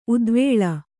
♪ udvēḷa